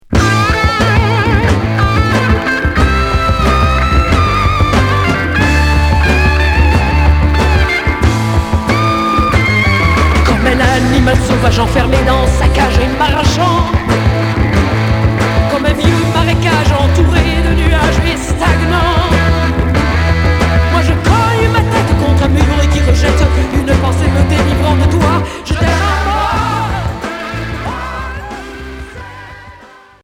Heavy pop